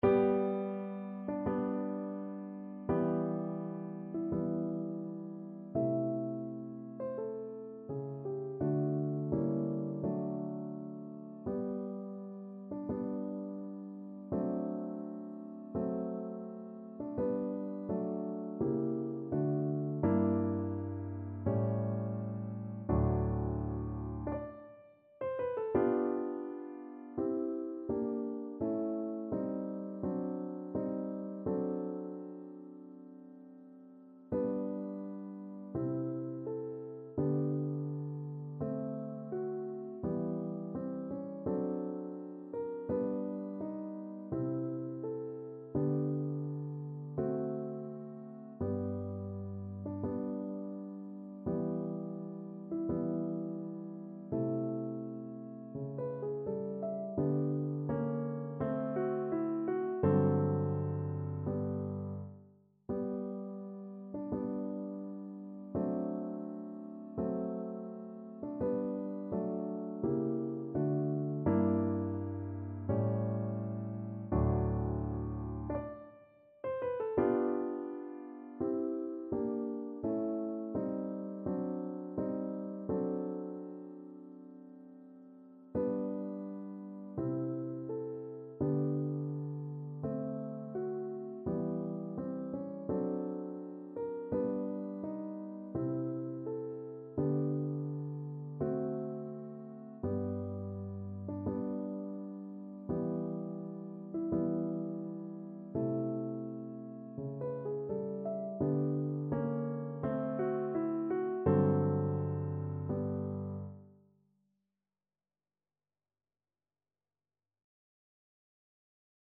2/4 (View more 2/4 Music)
G major (Sounding Pitch) (View more G major Music for Flute )
~ = 42 Sehr langsam
Classical (View more Classical Flute Music)